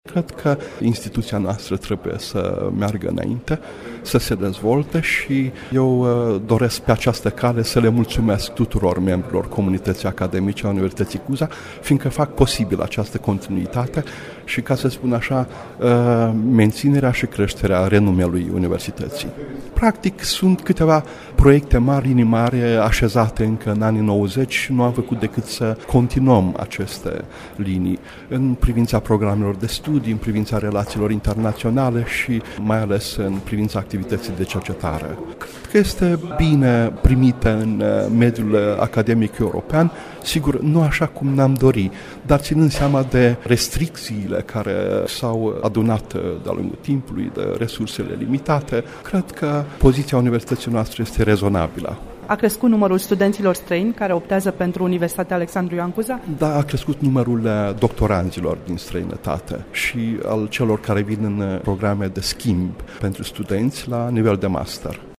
Rectorul instituţiei, prof. univ. dr. Vasile Işan, a vorbit despre importanţa acestui moment: